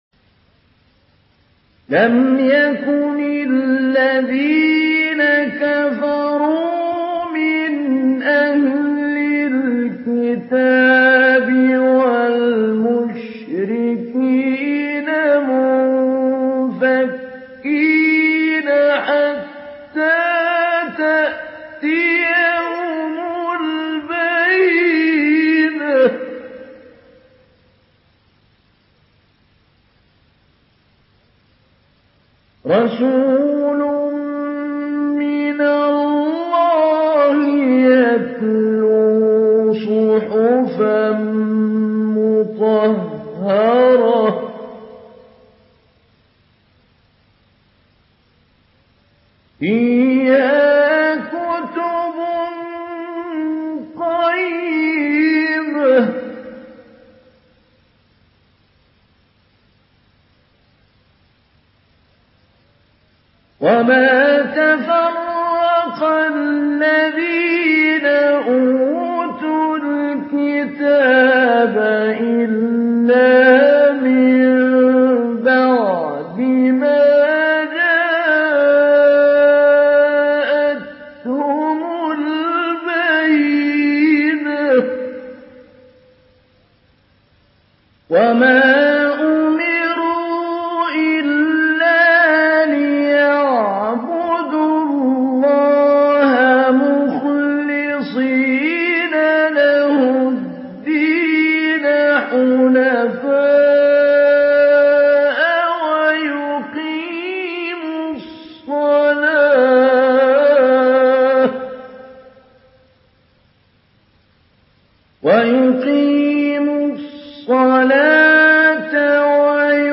سورة البينة MP3 بصوت محمود علي البنا مجود برواية حفص
مجود حفص عن عاصم